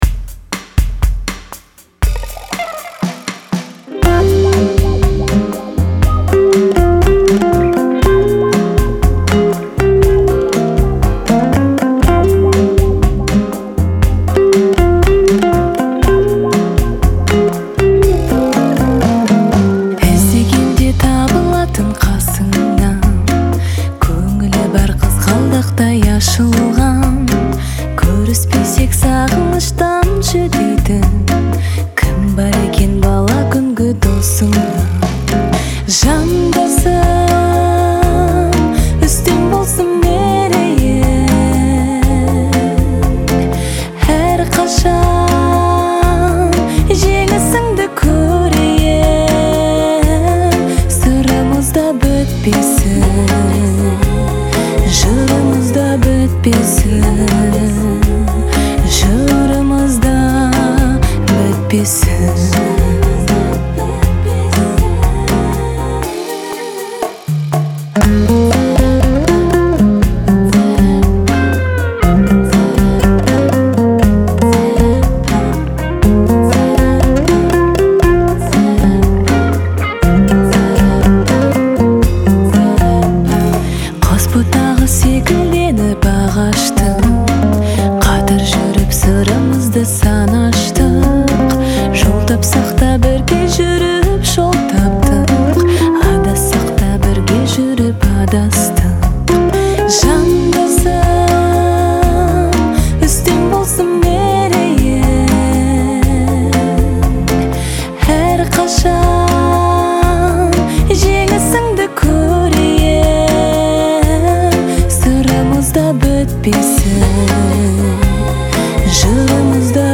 энергичная песня
относящаяся к жанру поп и хип-хоп.